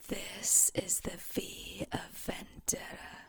speech-female_hprModel_residual
english female model residual sms-tools speak vocal voice sound effect free sound royalty free Memes